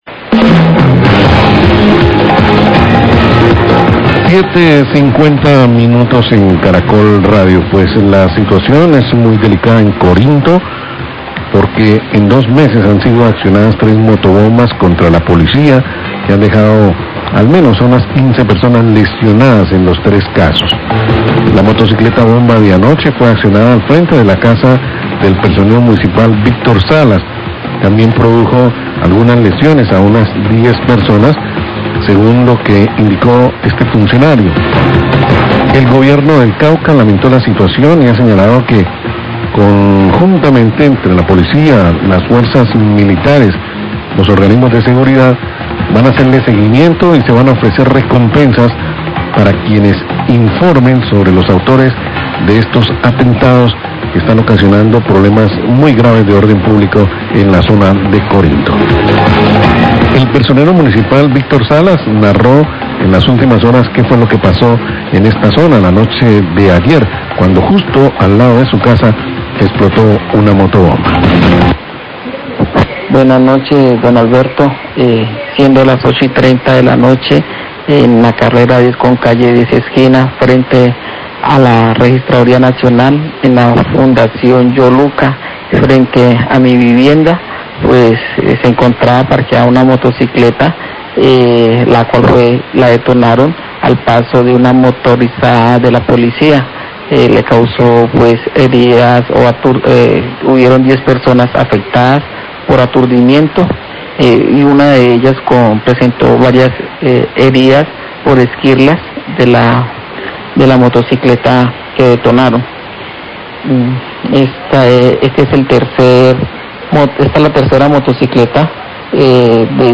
Radio
Hay tensión entre la comunidad de Corinto por los hechos de violencia ocurridos como el atentado con motobomba, hecho que se suma al asesinato del operario de la Compañía Energética, Declaraciones de Víctor Salas, Personero de Corinto y Eduard Garcia, Alcalde de Corinto.